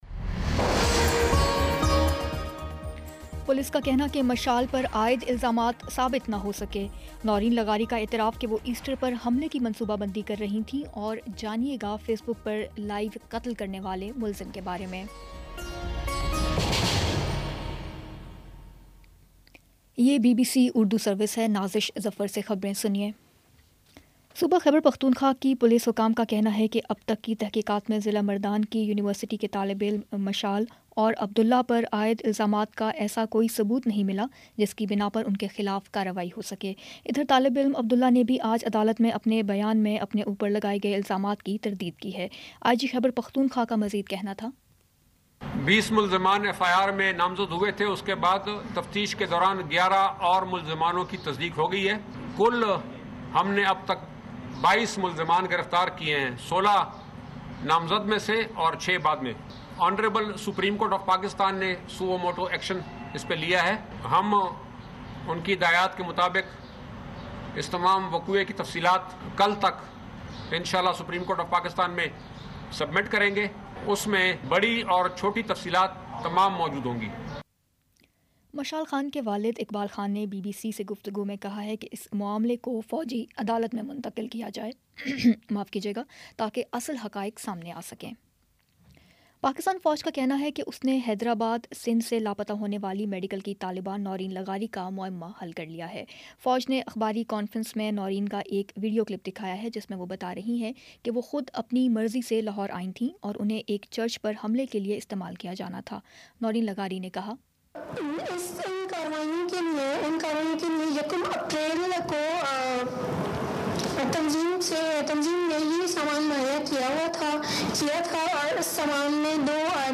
اپریل 17 : شام چھ بجے کا نیوز بُلیٹن